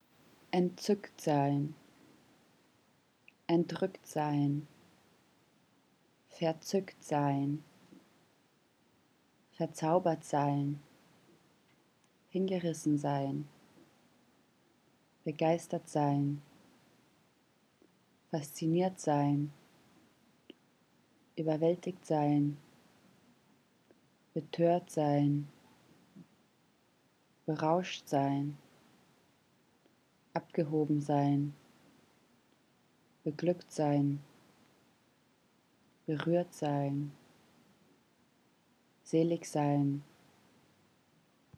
Die gebetsmühlenartige Wiederholung der gleichen Wortendungen greift den Herzrhythmus auf.
As in music, the eardrum begins to oscillate in time to the beat of the repeated words – a drummer in the ear, so to speak.
The sound of cascading words with identical endings can put a sensitive audience into a kind of trance.